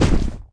drop_2.wav